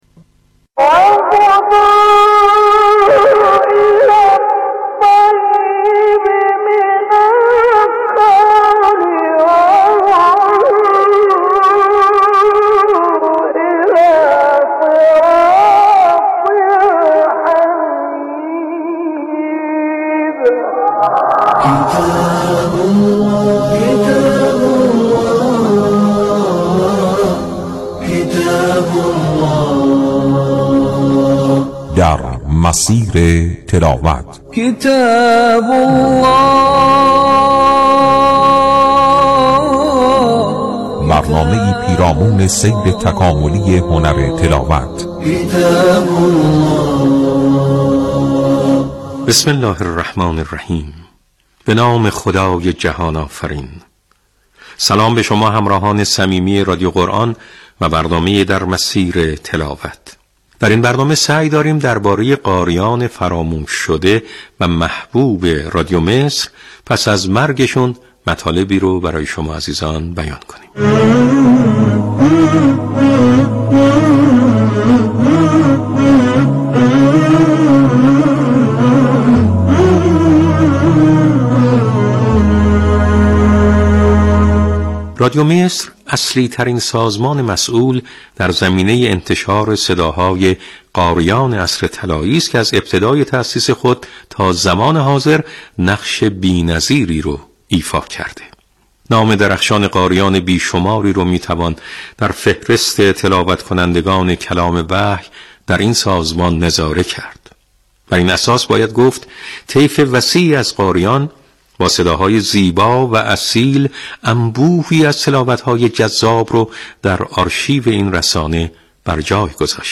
این برنامه که گفتار محور است و طی 4 تا 7 دقیقه پخش می‌شود، با هدف آشناسازی مخاطبان با مقاطعی جذاب از زندگی و شرح حال قاریان مشهور جهان اسلام، الگوسازی در حوزه هنر تلاوت قرآن و تقویت گرایش شنوندگان به استماع تلاوت‌های زیبا تهیه و تولید شده است.
معرفی مقاطعی از اوضاع و احوال قاریان مشهور جهان اسلام طی هر برنامه با یک موضوع، پخش فرازهای زیبا از تلاوت‌های قاری متناسب با محتوای ارائه شده در فواصل گفتار و ارائه نکات اخلاقی و قرآنی در حاشیه زندگی قاریان با ظرفیت الگوسازی از جمله موضوعات این برنامه است.